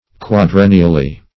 quadrennially - definition of quadrennially - synonyms, pronunciation, spelling from Free Dictionary Search Result for " quadrennially" : The Collaborative International Dictionary of English v.0.48: Quadrennially \Quad*ren"ni*al*ly\, adv. Once in four years.
quadrennially.mp3